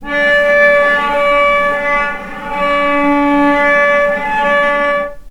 vc_sp-C#4-mf.AIF